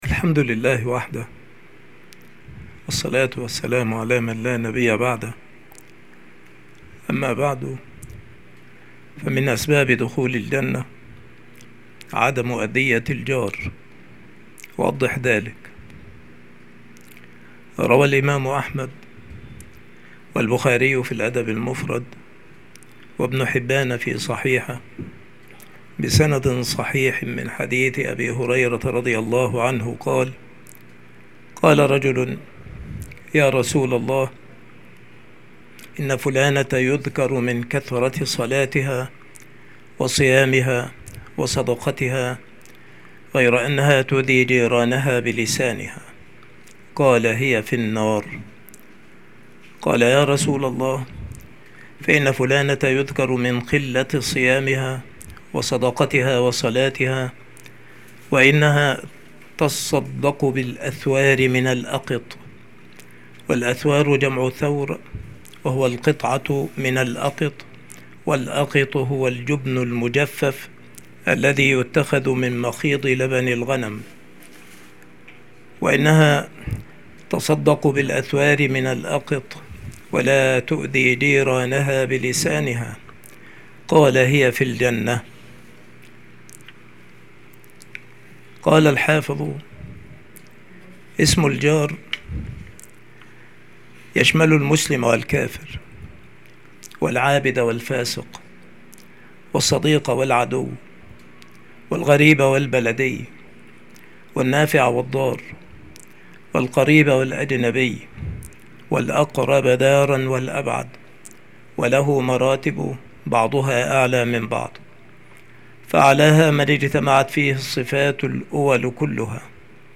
التصنيف : عقيدتنا الإسلامية [ مقاطع موجزة ]
• مكان إلقاء هذه المحاضرة : المكتبة - سبك الأحد - أشمون - محافظة المنوفية - مصر